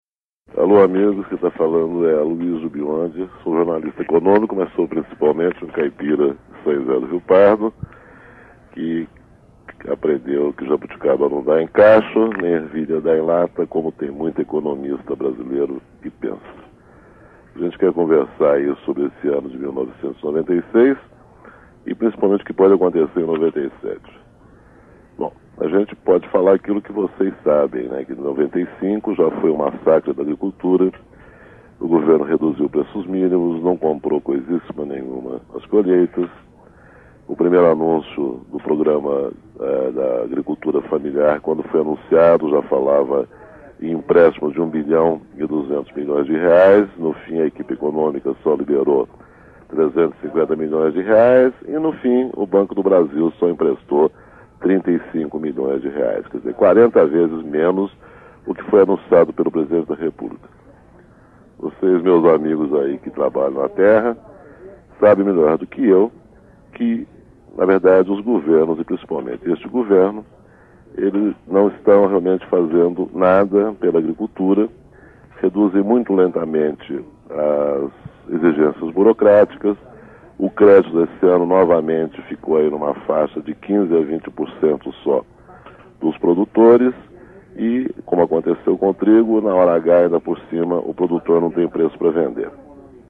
Nesta edição 194, veiculada no dia 16 de dezembro de 1996, o jornalista Aloysio Biondi gravou uma carta falada de cerca de 14 minutos para fazer um balanço da agricultura no ano e mostrar sua expectativa com o ano seguinte.